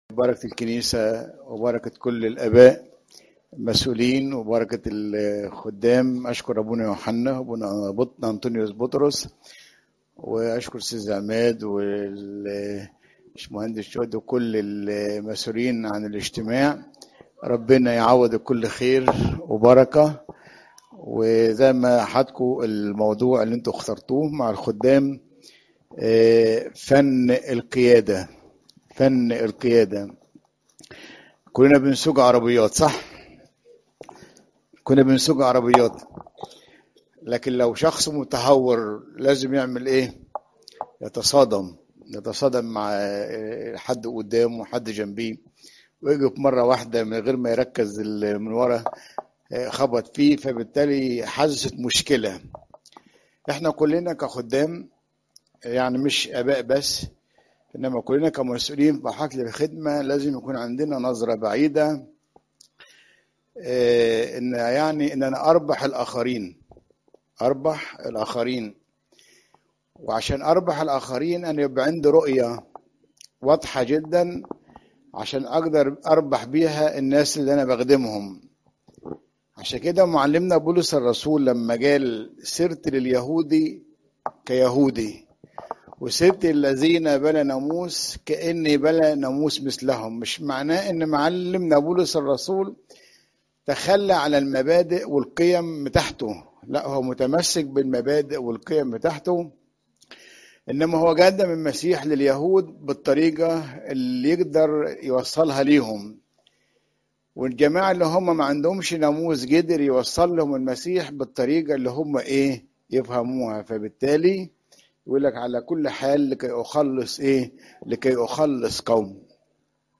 تفاصيل العظة